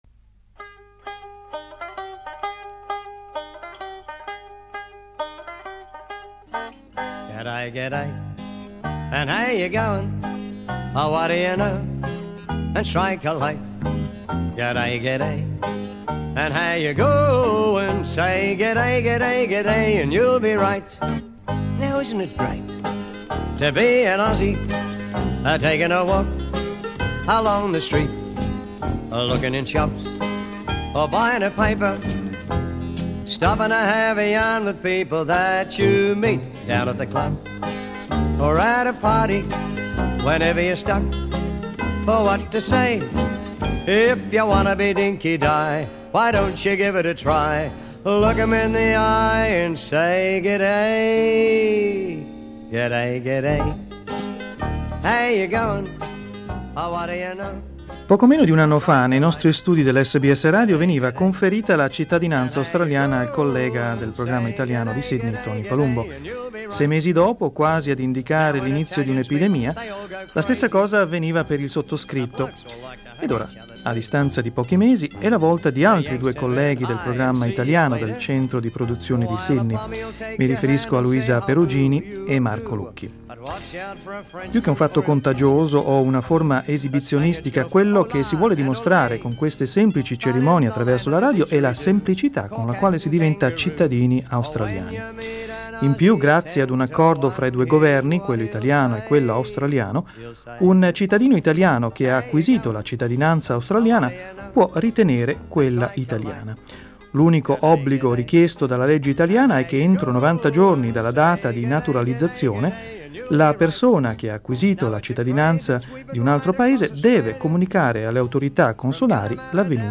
SBS Italian broadcasters
take the oath to become Australian citizens live on air on SBS Radio in 1996.